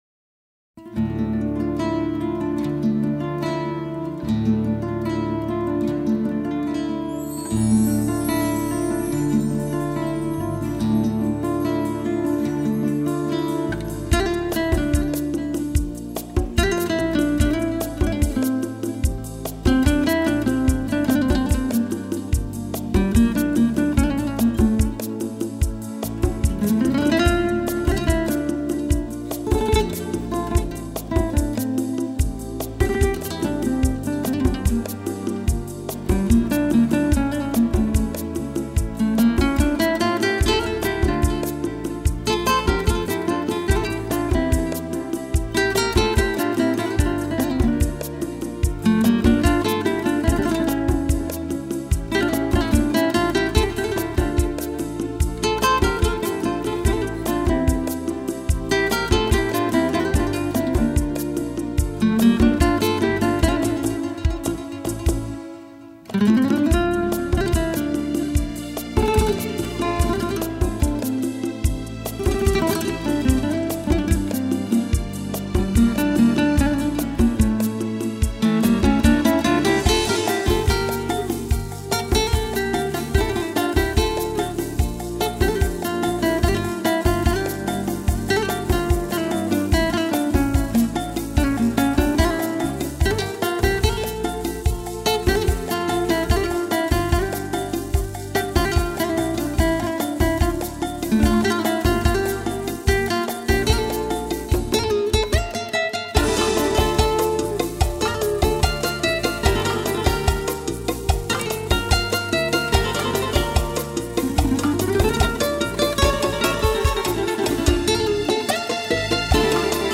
Enjoy with tuning tone